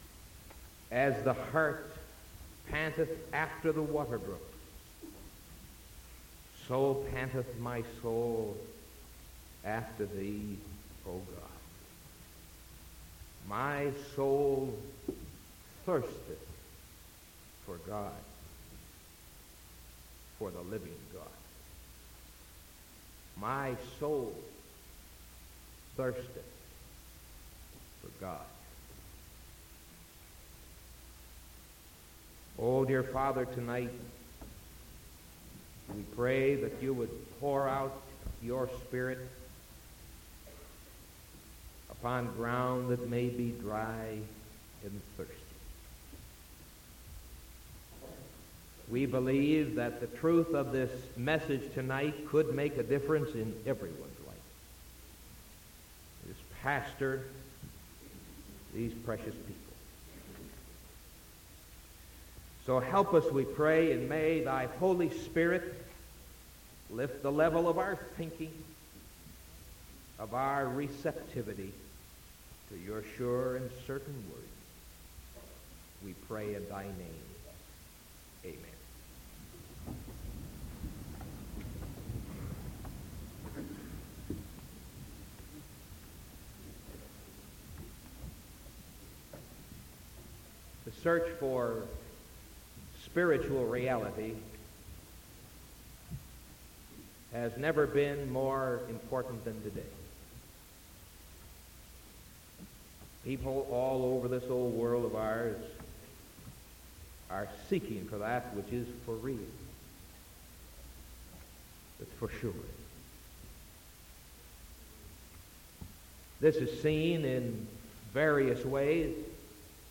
Sermon from January 12th 1975 PM